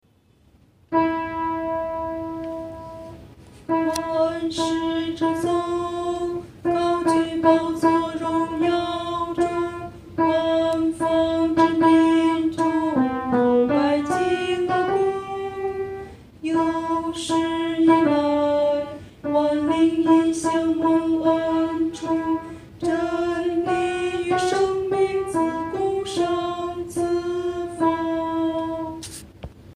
独唱（第二声）